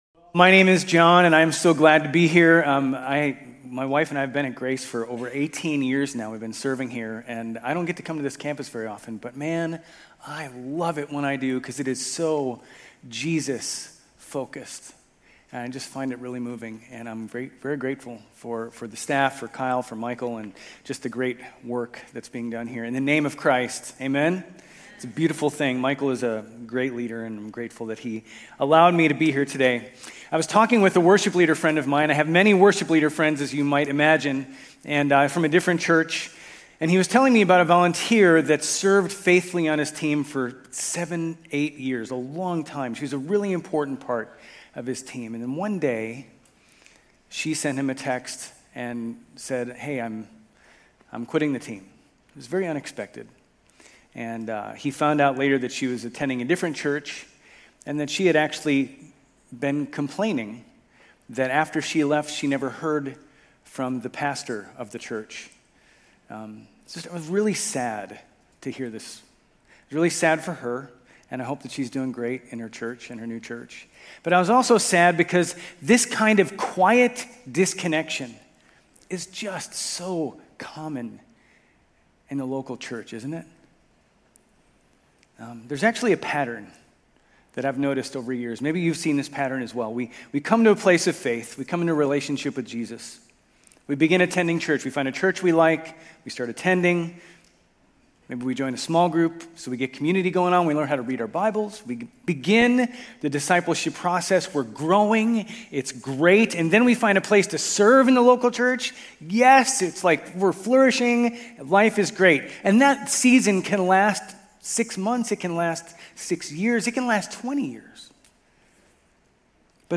Grace Community Church University Blvd Campus Sermons 8_3 University Blvd Campus Aug 04 2025 | 00:34:21 1x 00:00 / 00:34:21 Subscribe Share RSS Feed Share Link Embed